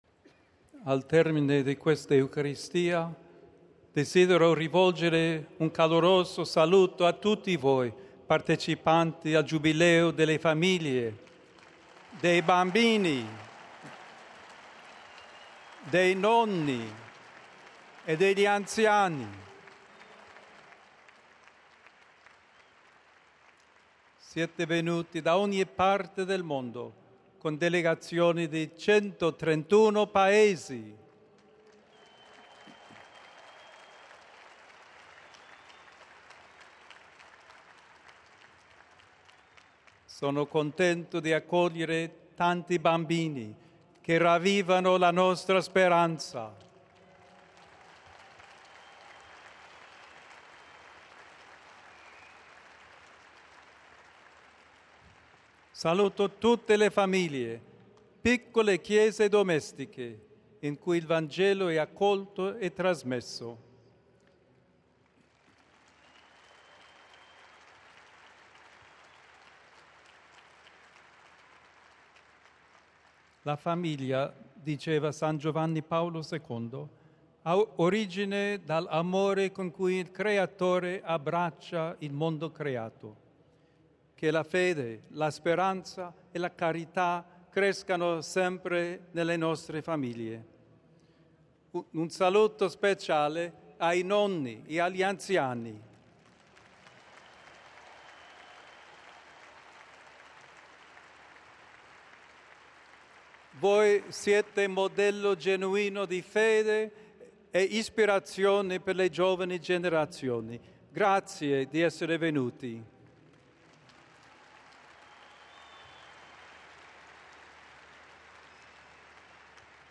This podcast offers the public speeches of the Holy Father, in their original languages.